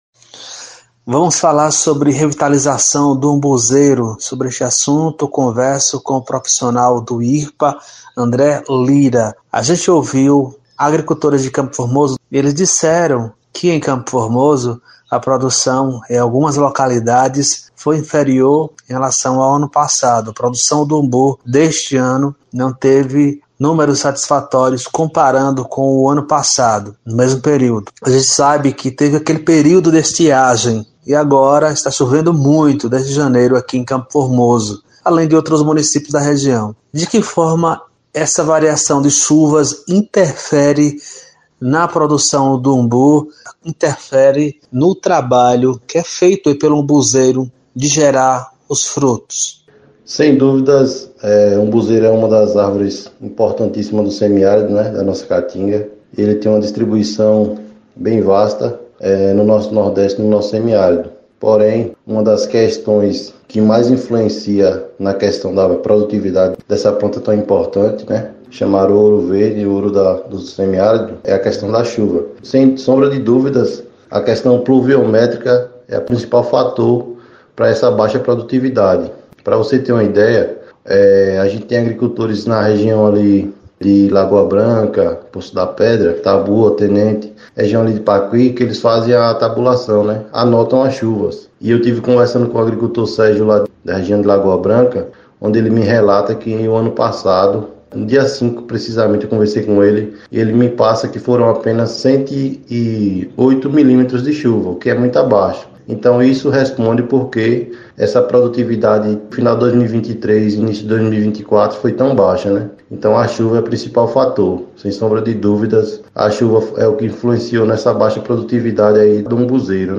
Técnico agropecuário